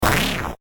sons de trrring